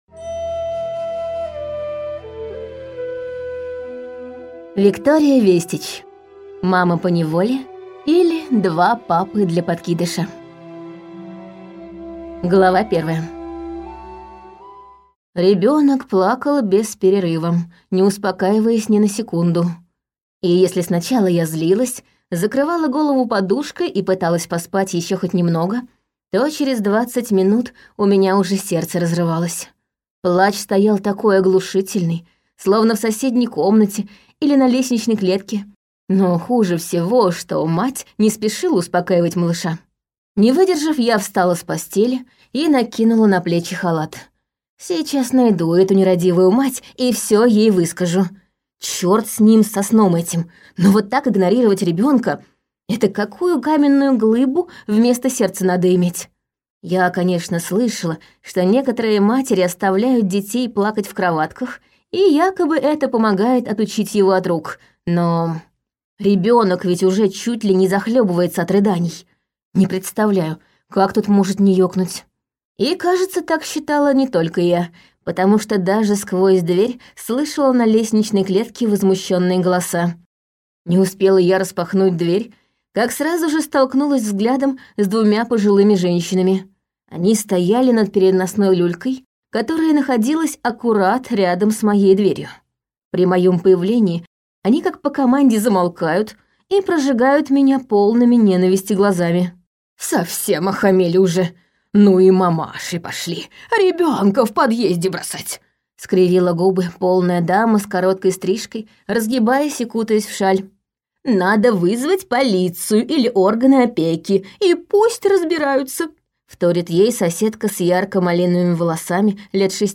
Аудиокнига Мама поневоле, или два папы для подкидыша | Библиотека аудиокниг